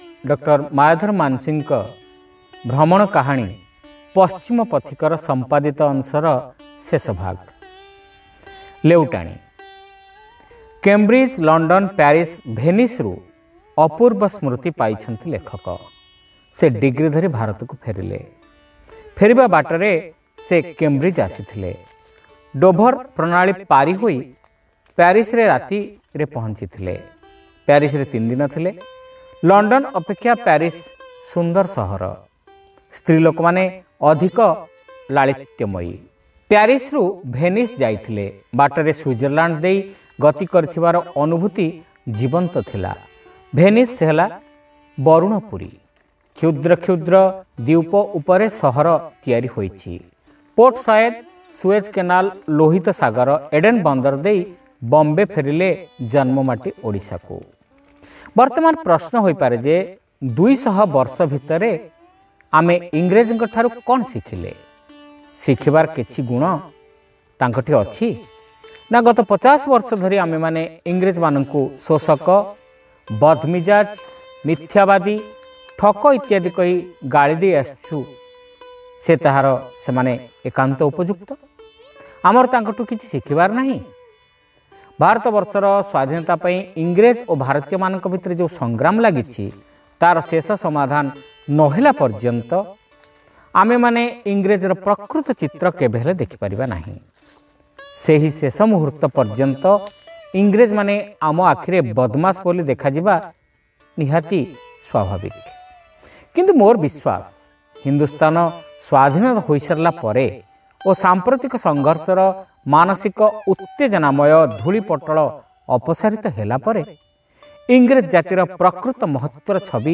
ଶ୍ରାବ୍ୟ ଗଳ୍ପ : ପଶ୍ଚିମ ପଥିକର ସମ୍ପାଦିତ ରୂପ (ଅଷ୍ଟମ ଭାଗ)